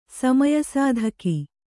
♪ samaya sādhaki